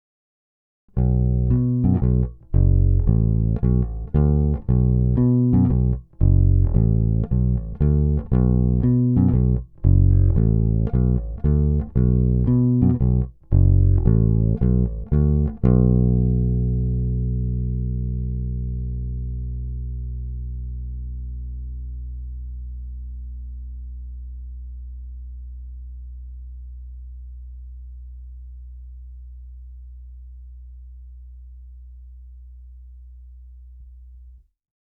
Snímač je kousavější, agresívnější, a to i přes použité hlazené struny Thomastik-Infeld Jazz Flat Wound JF344 (recenze), jinak klasický precižnovský charakter se nezapře. S nimi jsem provedl nahrávku rovnou do zvukovky.